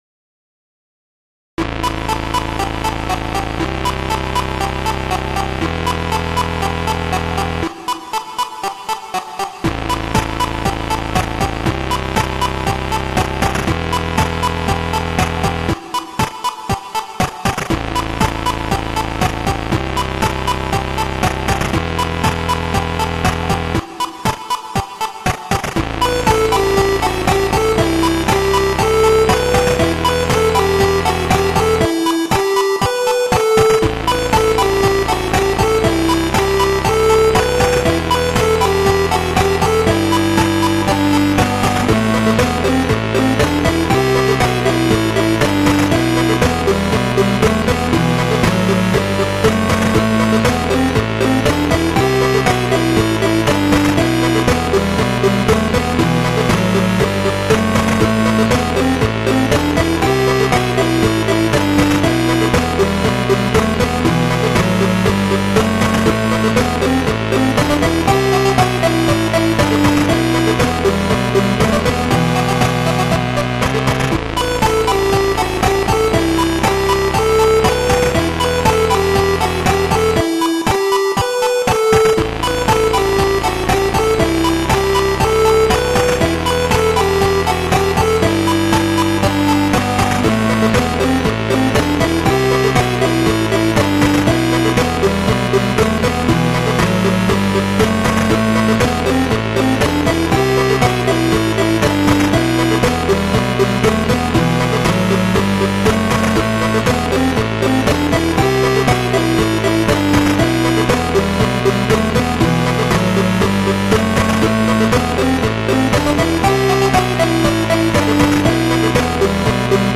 So if you like music that sounds like it was made for a NES and don’t mind abrupt endings, you can check out some of the stuff I’ve been working on: